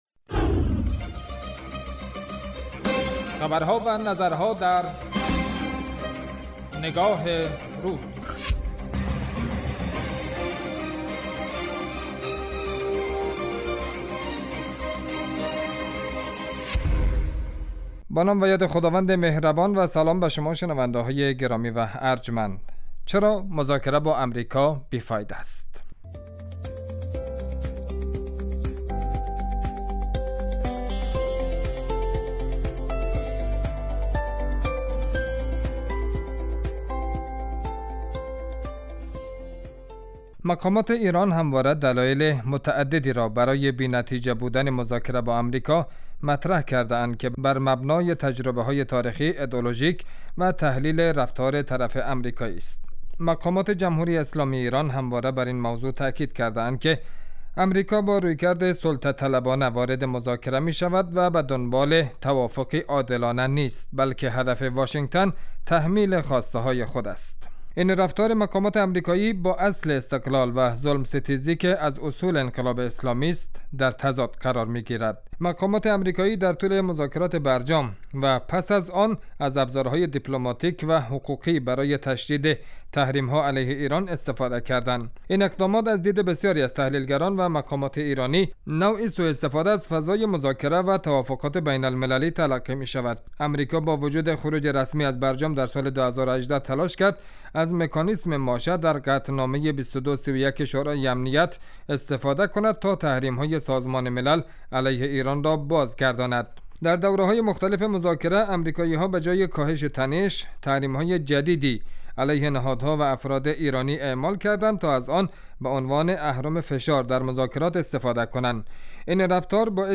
رادیو